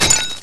buff_frozen.wav